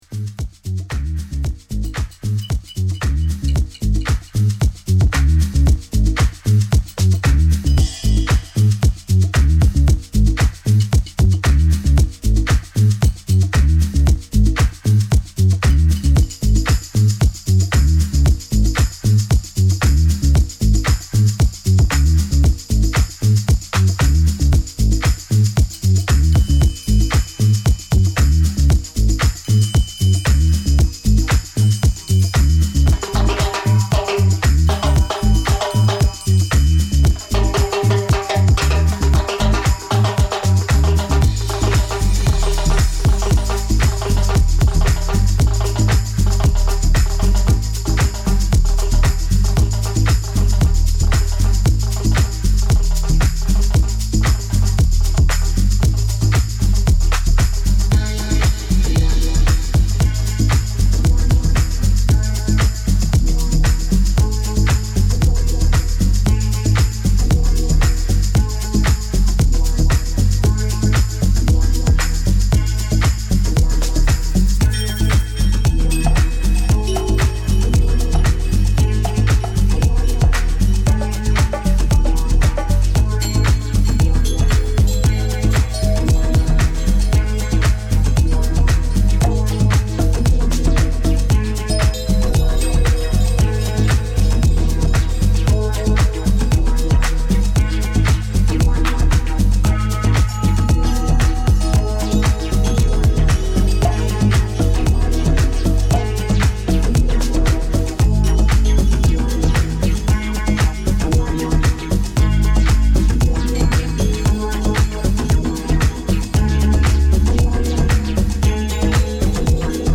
It includes a nice variety of bits, and has a good flow.